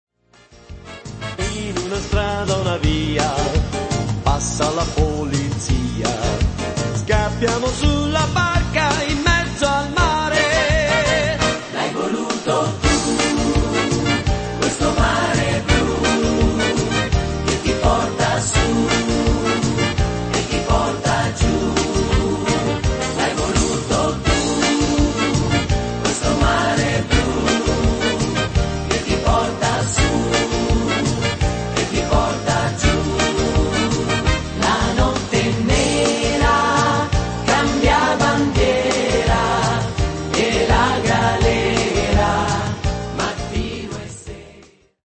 duinato